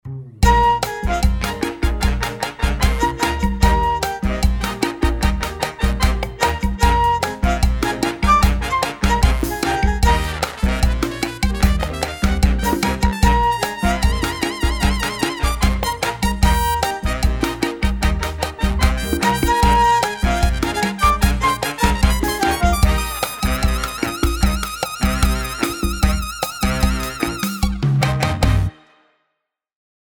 Ethnic and World, Whimsy, Kids, Retro and Holiday